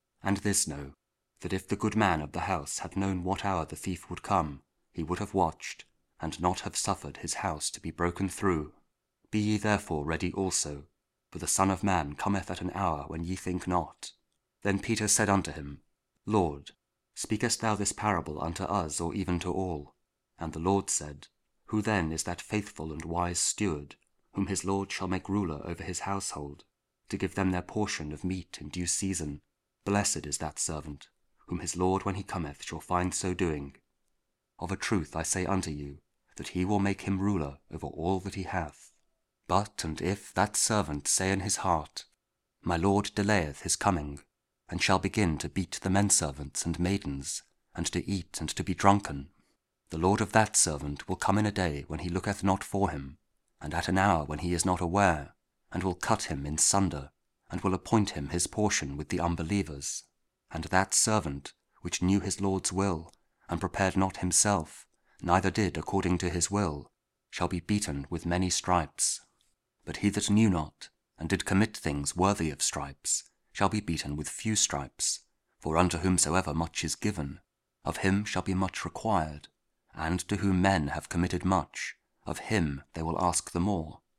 Luke 12: 39-48 | King James Audio Bible | KJV | Parables Of Jesus | Week 29 Wednesday